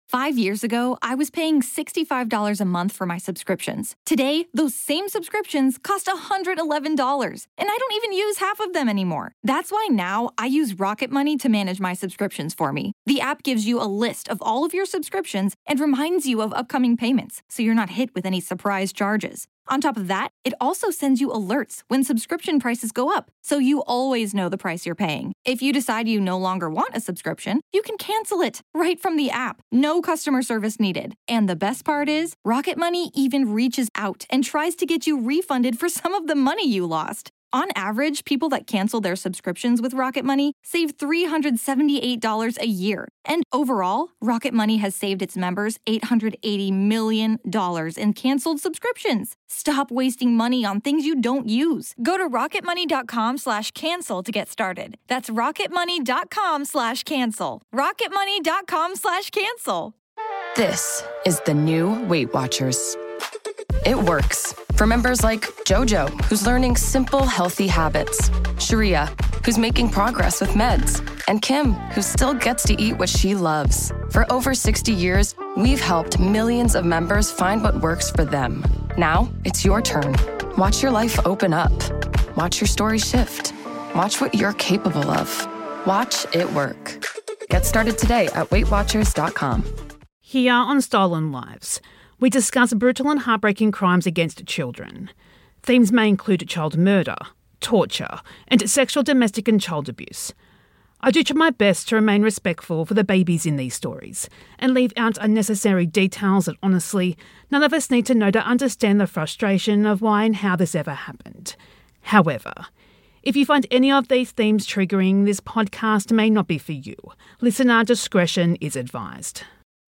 This is a re-release with sped up audio and gaps of silence removed.